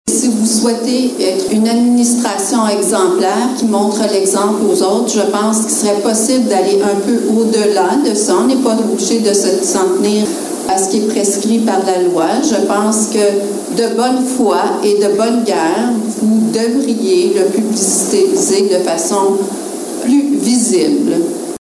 C’est ce que l’une d’entre eux a déclaré devant les élus au conseil de ville du 14 novembre.